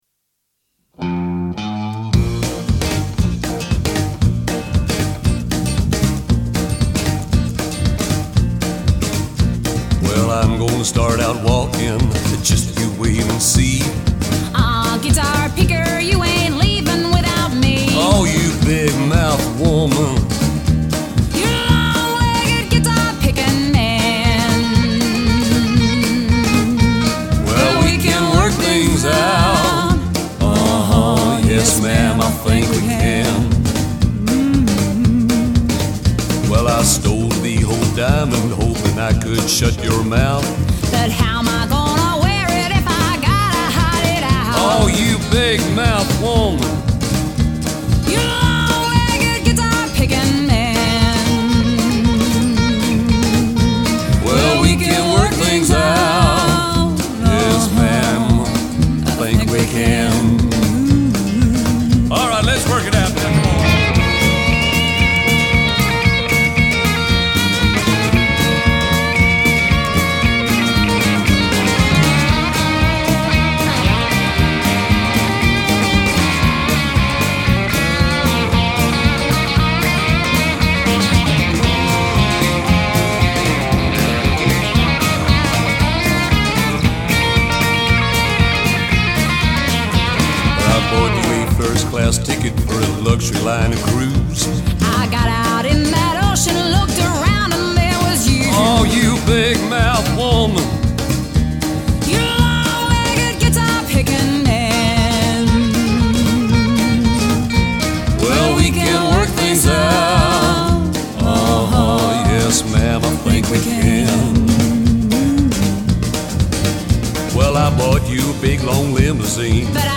A Rockabilly/Country track
drums